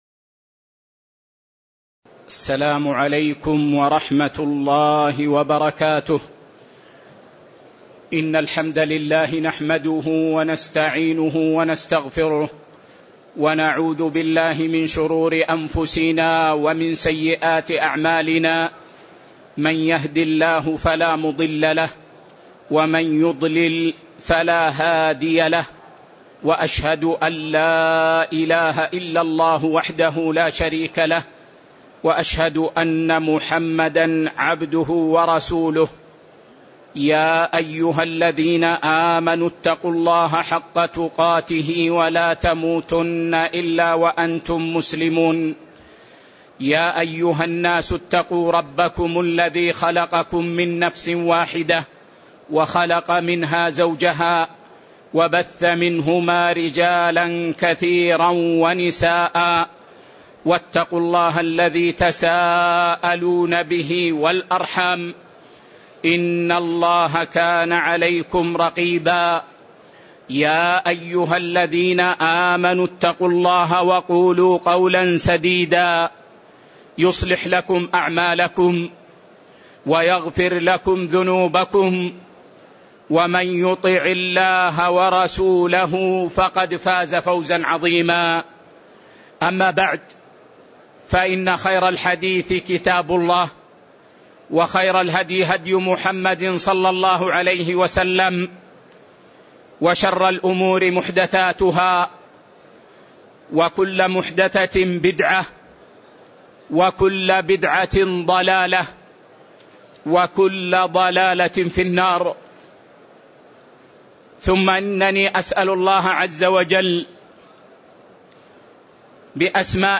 تاريخ النشر ٢١ رمضان ١٤٣٩ هـ المكان: المسجد النبوي الشيخ